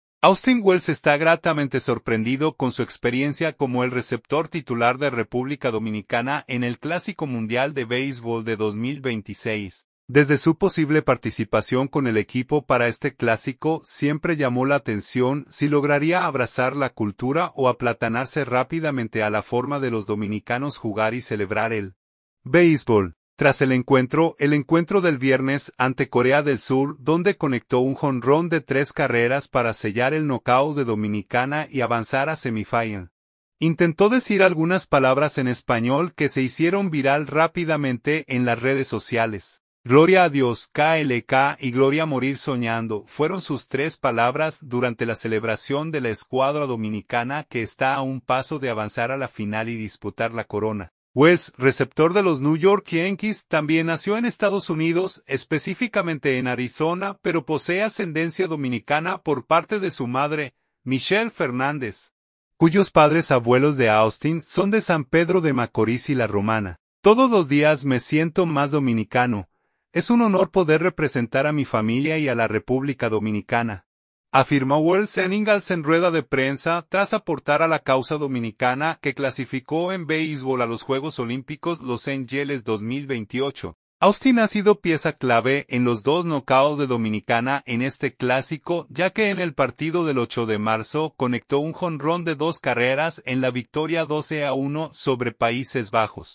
"Gloria a Dios, klk y Gloria morir soñando", fueron sus tres palabras durante la celebración de la escuadra dominicana que está a un paso de avanzar a la final y disputar la corona.